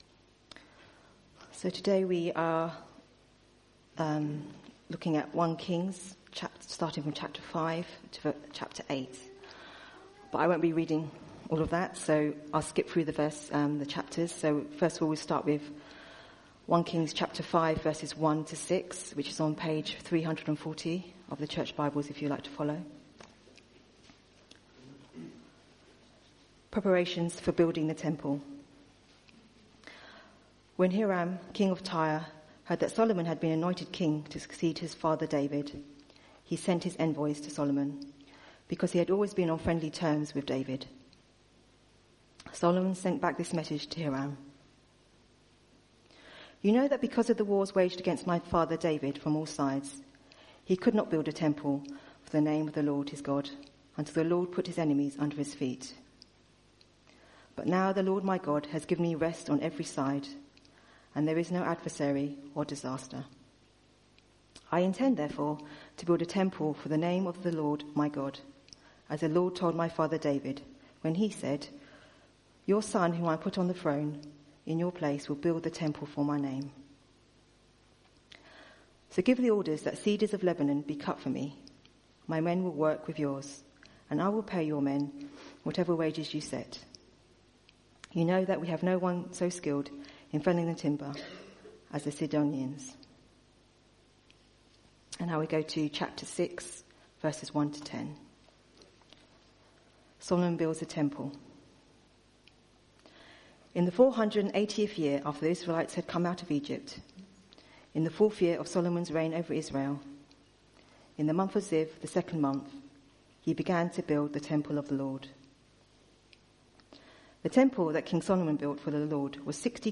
Media for Sunday Service
Theme: Solomon Builds the temple Sermon